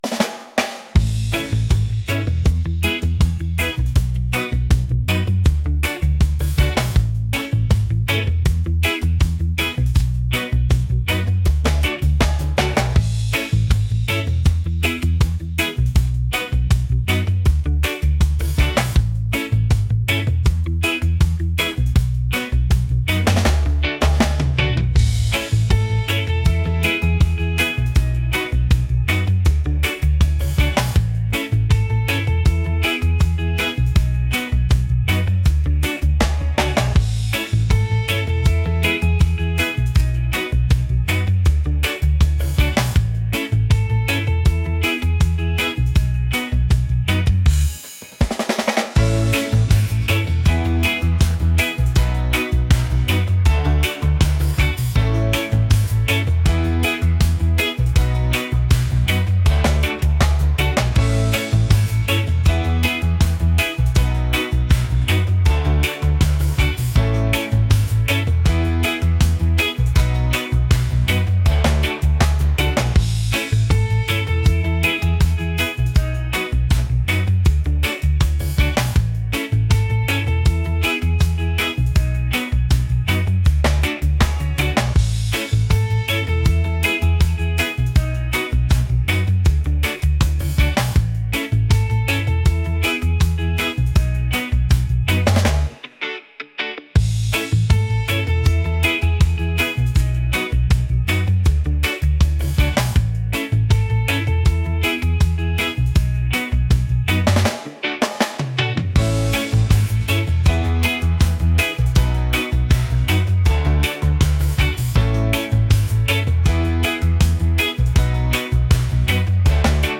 upbeat | catchy | reggae